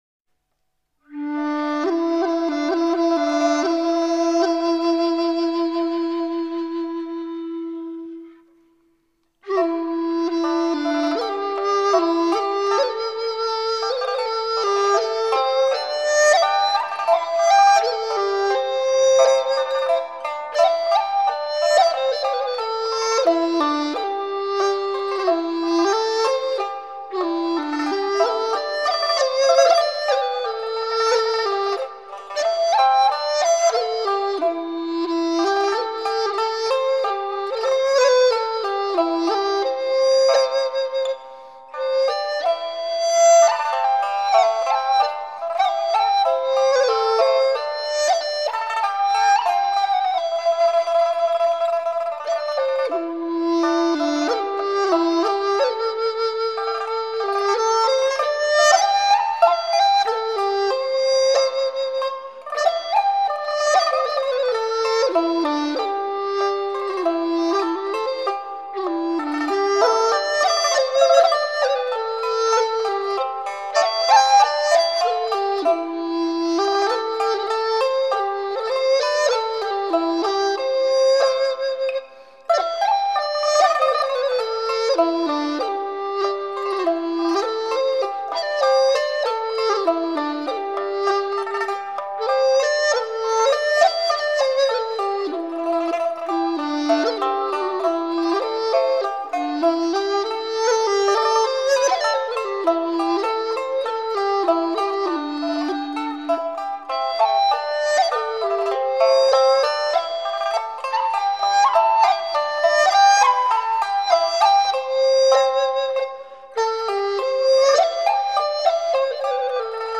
旋律清新流畅，细腻柔美，富有浓郁的江南韵味
即将节拍逐层成倍扩充，而速度逐层放慢，旋律一次又一次地加花，清新流畅，细腻柔美，富有浓郁的江南韵味。